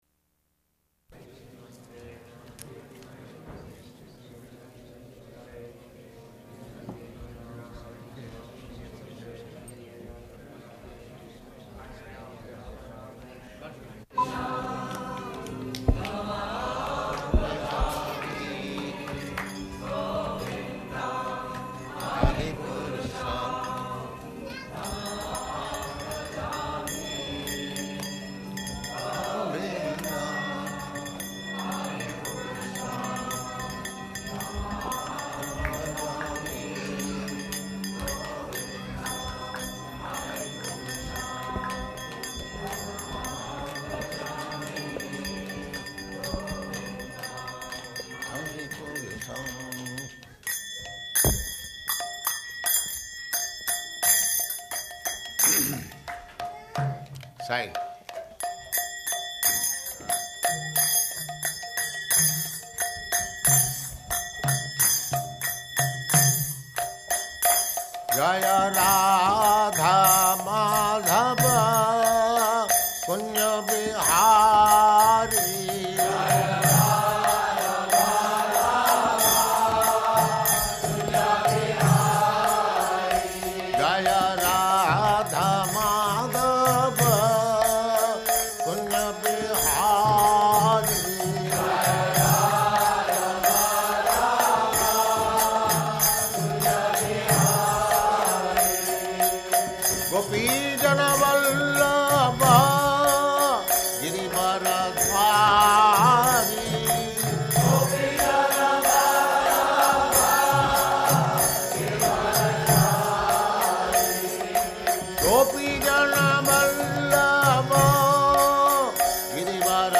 Location: Los Angeles
[ Govindam prayers playing; sings along] [leads singing of Jaya Rādhā-Mādhava ]